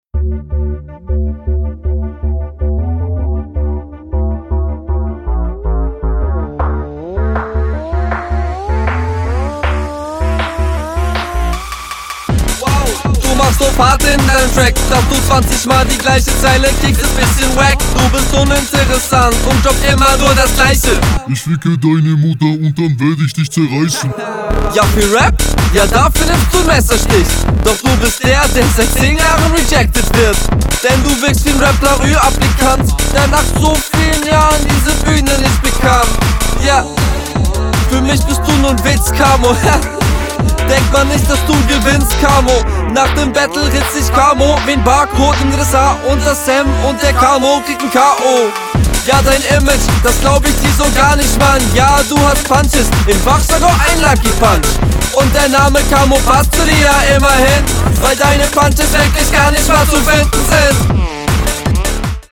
Geiler Beat
geiler beat, schöne runde